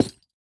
Minecraft Version Minecraft Version 1.21.5 Latest Release | Latest Snapshot 1.21.5 / assets / minecraft / sounds / block / cherry_wood_hanging_sign / break3.ogg Compare With Compare With Latest Release | Latest Snapshot
break3.ogg